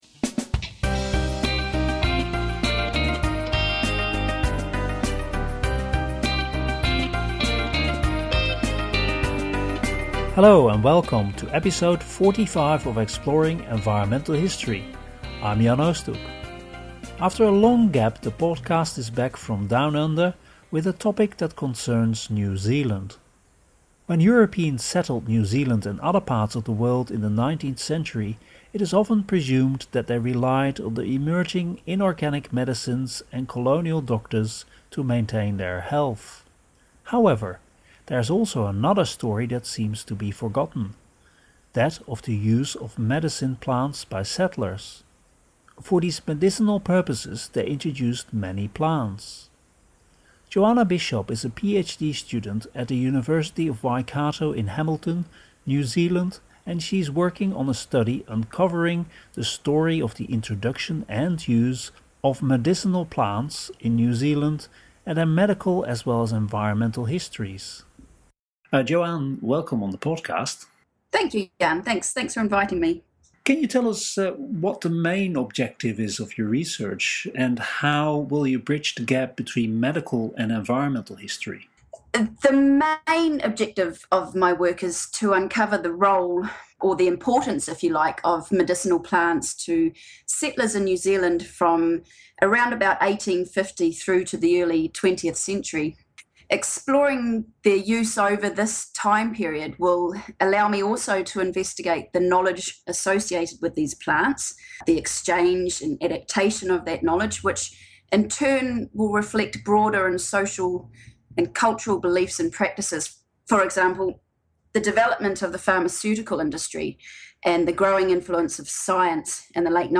Exploring Environmental History podcasts are periodic programmes featuring interviews with people working in the field, reports on conferences and discussions about the use and methods of environmental history.